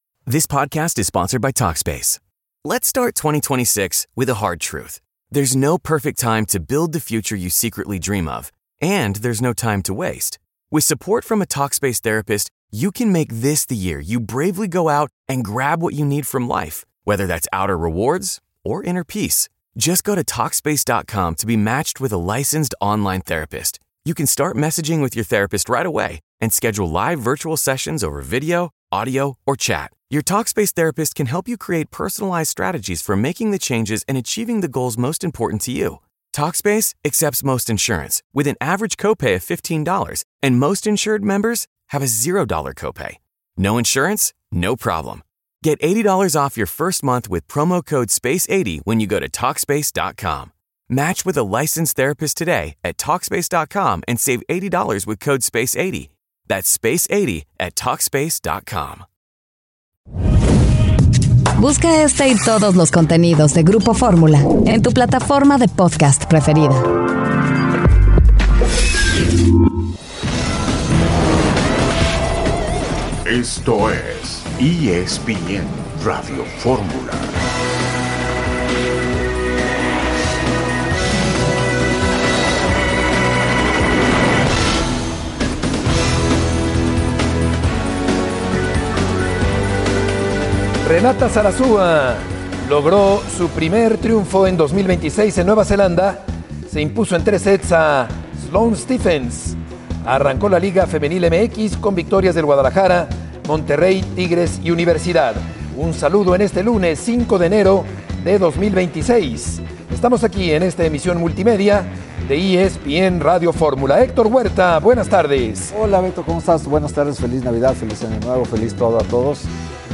Introductory Press Conference